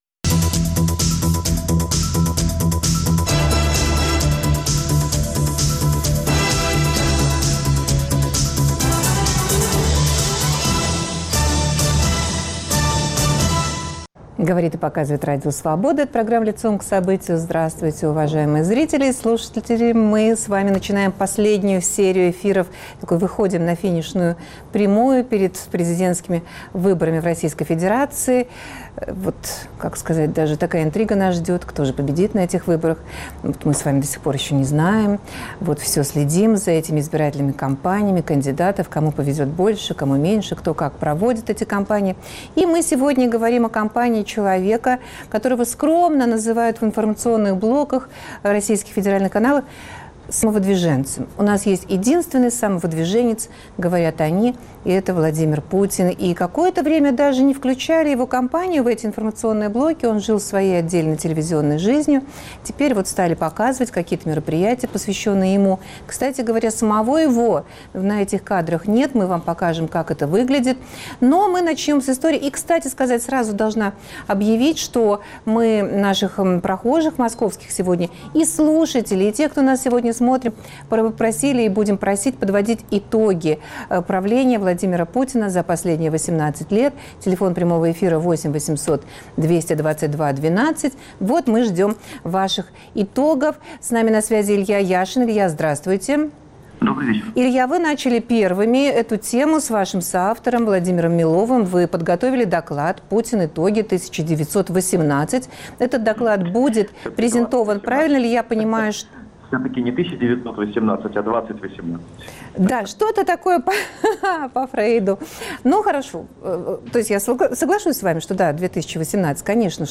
На связи соавтор доклада "Путин. Итоги. 2018" Илья Яшин